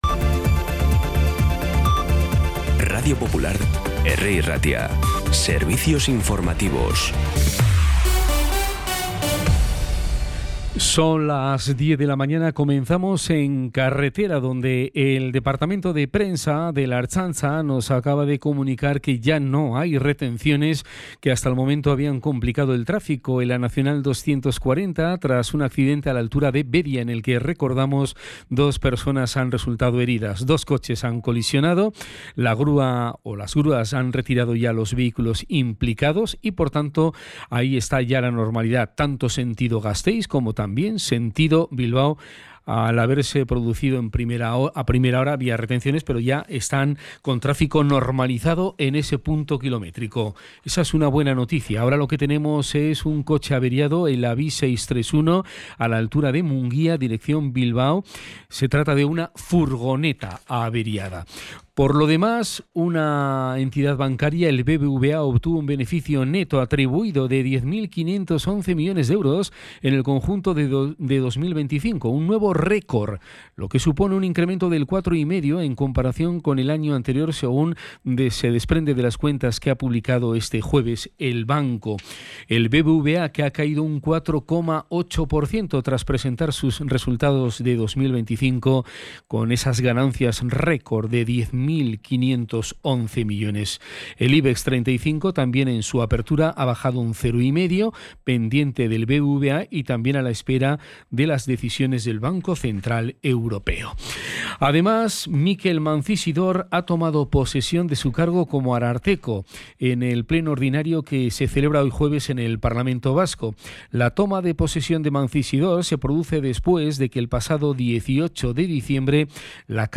Las noticias de Bilbao y Bizkaia del 5 de febrero a las 10
Los titulares actualizados con las voces del día.